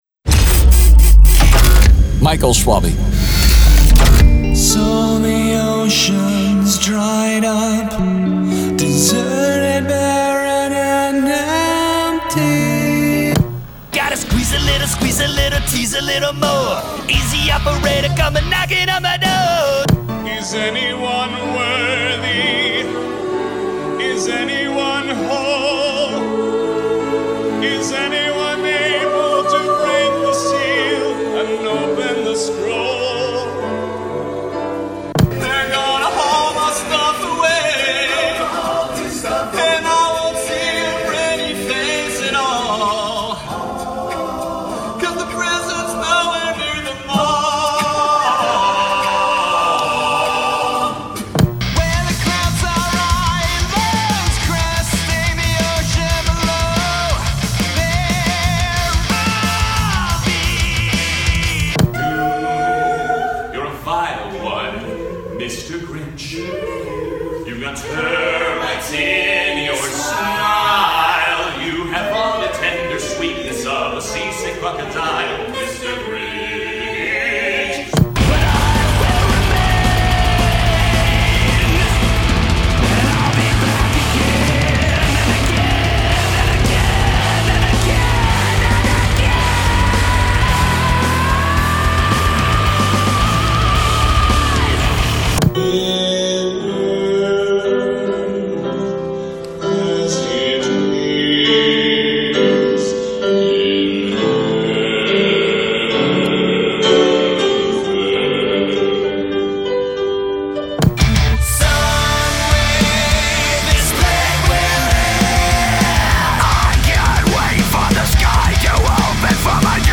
Guttural pig demon noises.